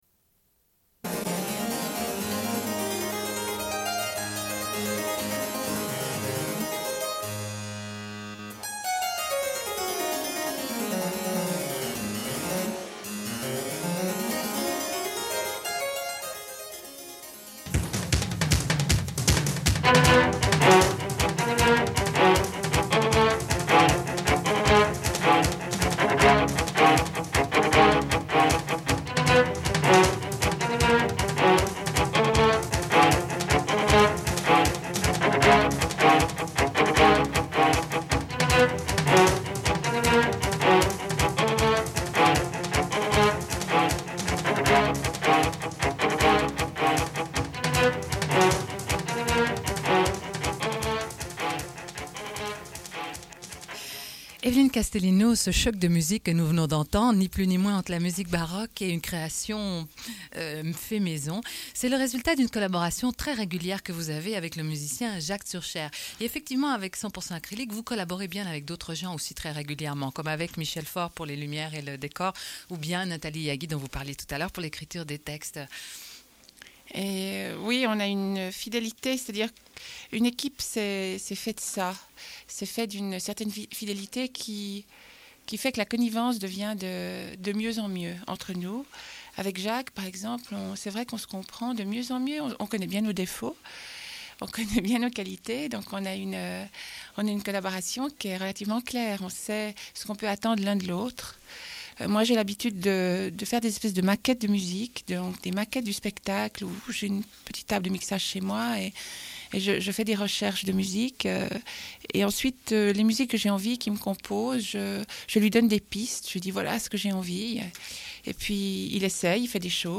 Une cassette audio, face B28:46